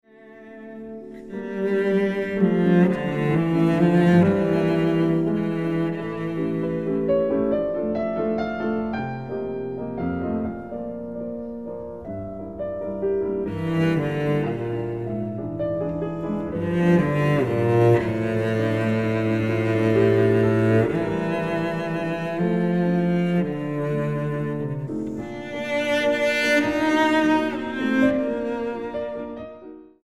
violoncello
piano